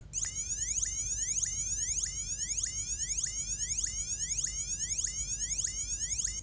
Alarm.m4a